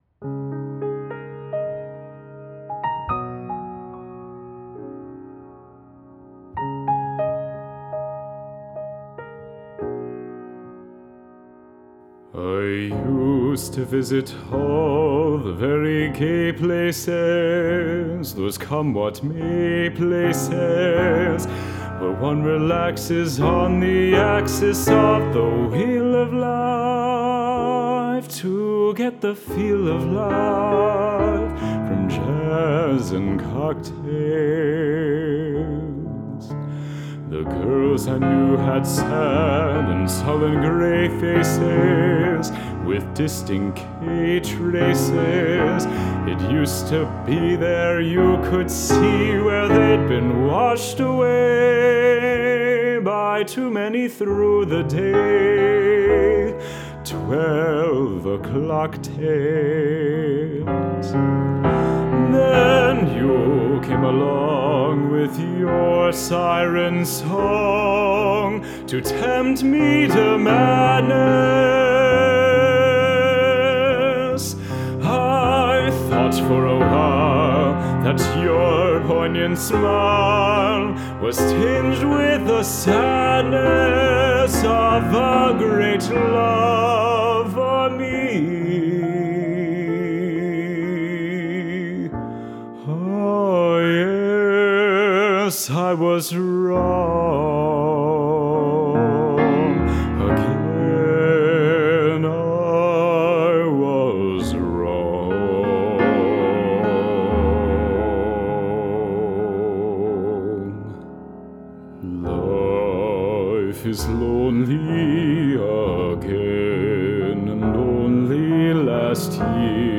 Jazz:
Voice
Piano